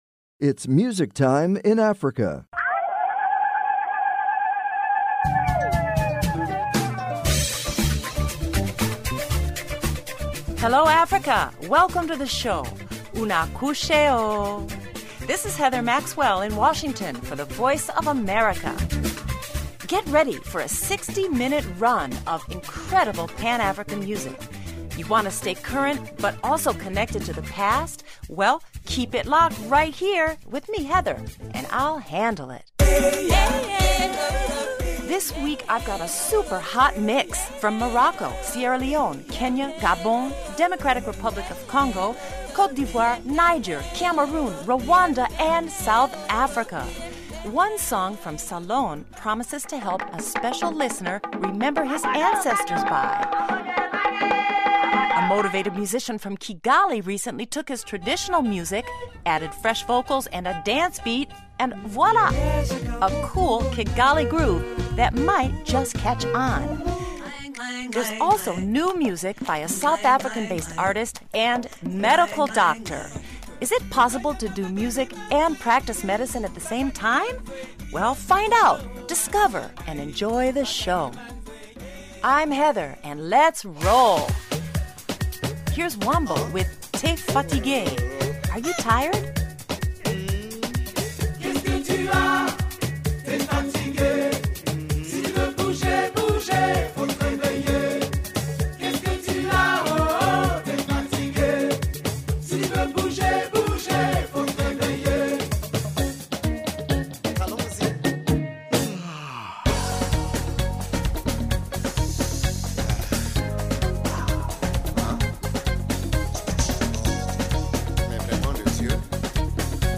exclusive interviews
rare recordings